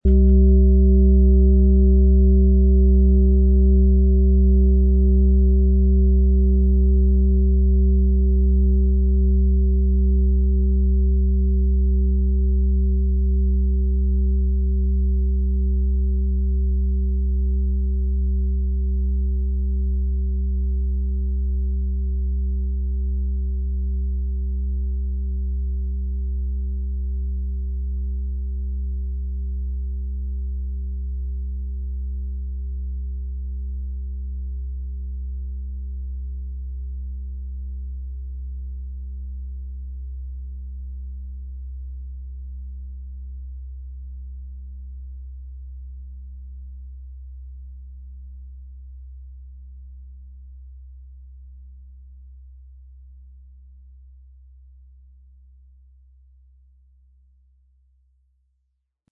XXXL Planeten-Fußschale mit der Kraft der Sonne und der Erdung des Tagestons, Durchmesser Ø 45 cm, ca. bis Schuhgröße 41, 9,05 kg., mit Klöppel
Planetenton 1
Erfahrene Handwerker schaffen mit Geduld und Sorgfalt Schalen, die lichtvolle, lebensfreudige Schwingungen und tiefe Erdung tragen.